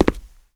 Footstep02.wav